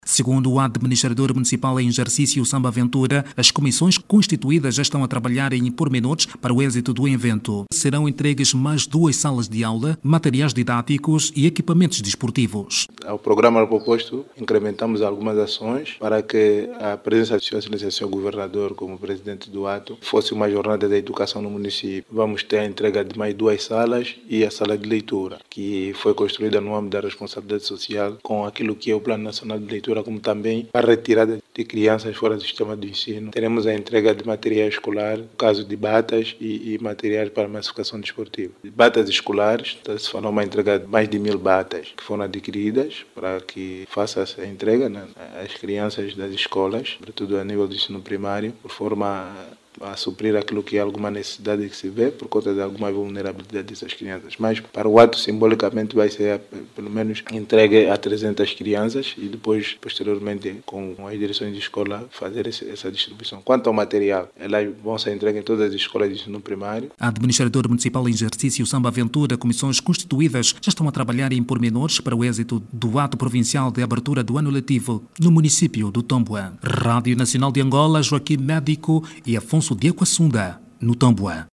O Município do Tômbwa, na Província do Namibe, vai ganhar novas salas de aula, espaço de leitura e material para massificação do desporto ainda este ano. A entrega acontece no acto de abertura do no lectivo a ter lugar no mesmo município. Clique no áudio abaixo e ouça a reportagem do